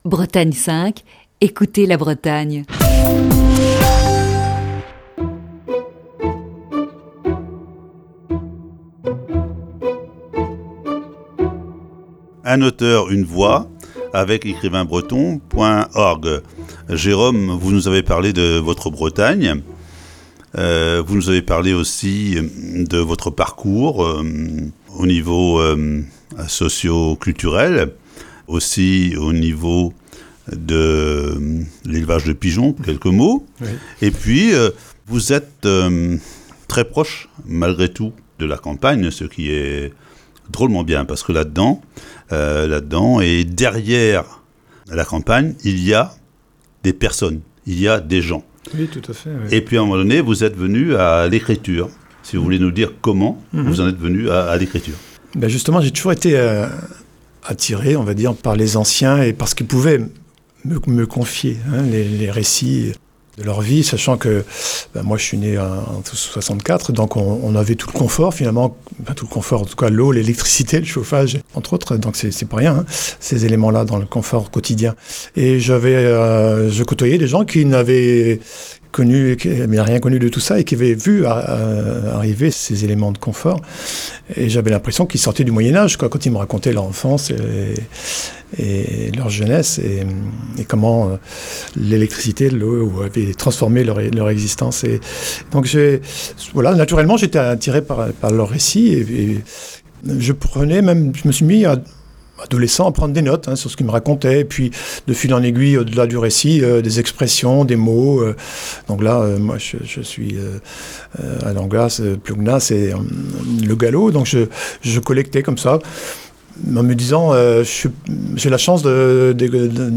série d'entretiens